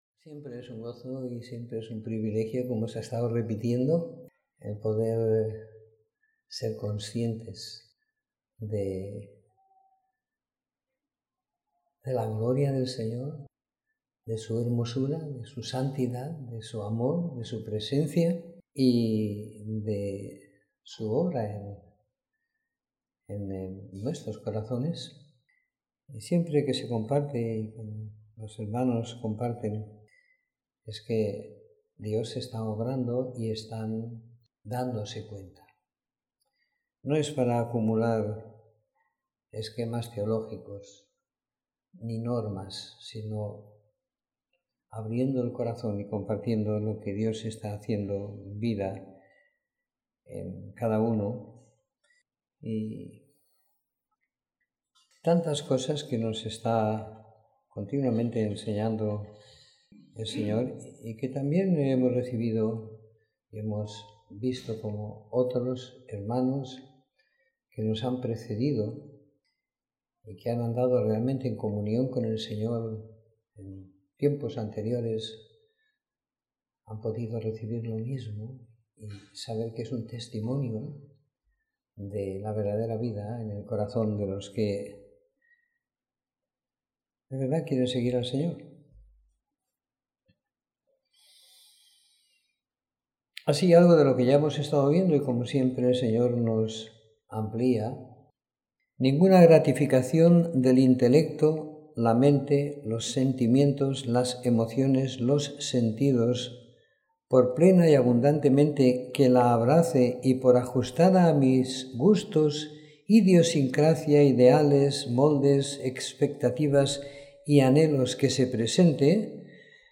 Reunión semanal de compartir la Palabra y la Vida.